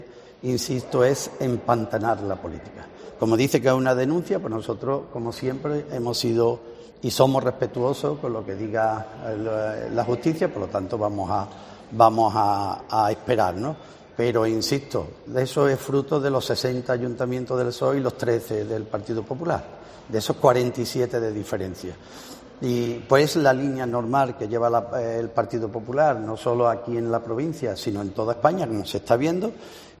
Iganacio Caraballo, presidentede la Diputación
Unas declaraciones que ha realizado en el cónclave de alcaldes celebrado en el Foro Iberoamericano de la Rábida